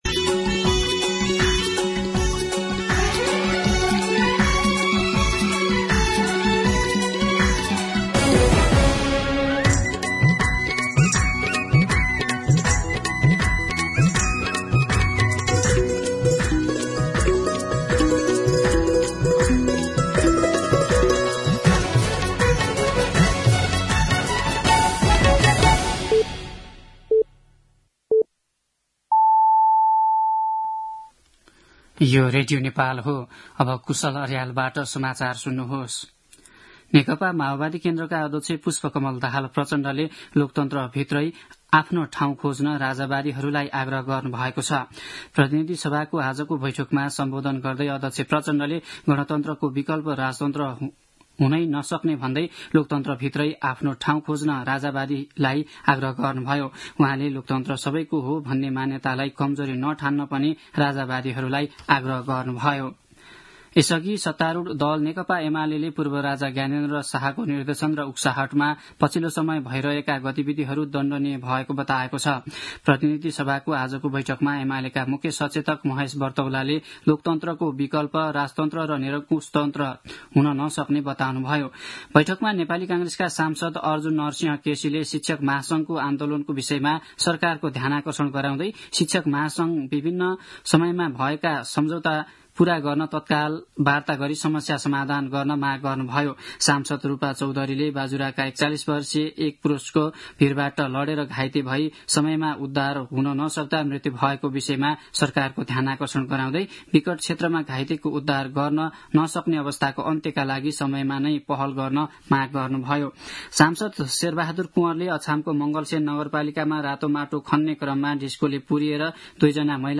दिउँसो ४ बजेको नेपाली समाचार : २८ फागुन , २०८१
4pm-News-11-27.mp3